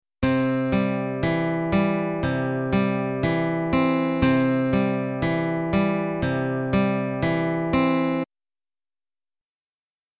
When using the technique, the thumb alternates between bass strings (strings 4, 5, or 6) while playing steady quarter notes.  While this is happening a melody is played using the higher strings (1, 2, and 3).
Travis Picking - Alternating Bass with Melody
e3_travispicking.mp3